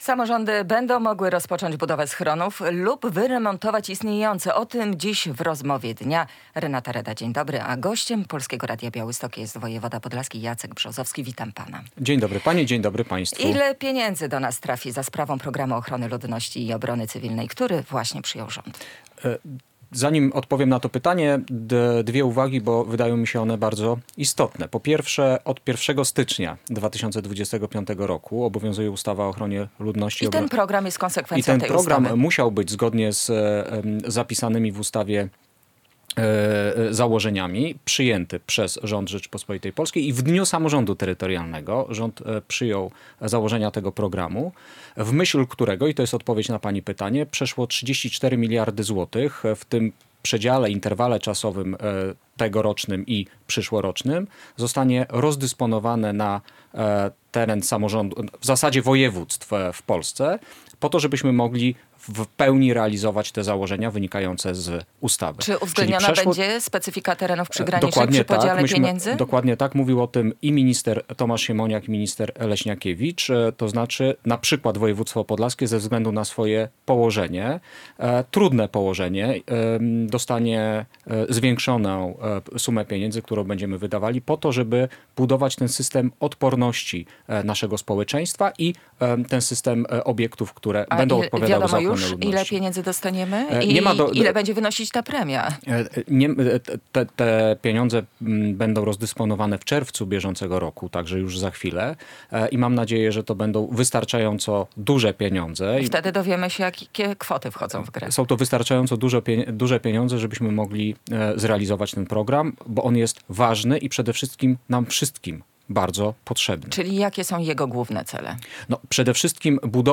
Radio Białystok | Gość | Jacek Brzozowski - wojewoda podlaski